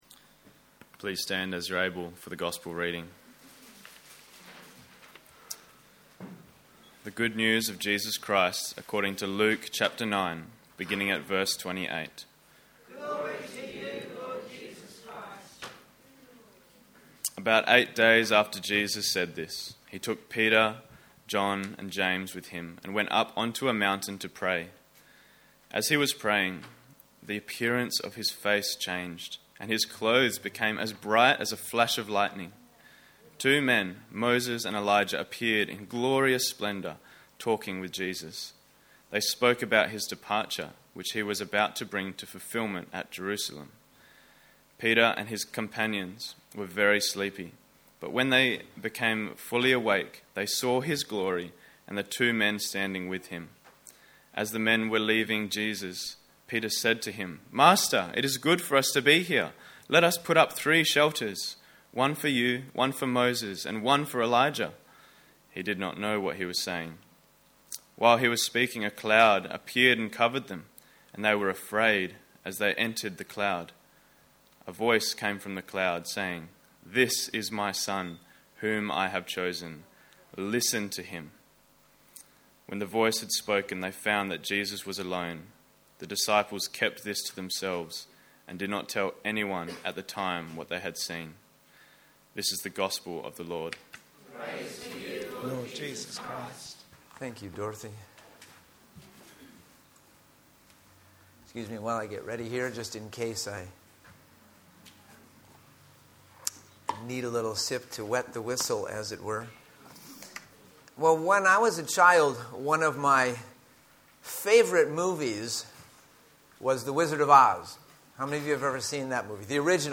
Sermons | Living Water Anglican Church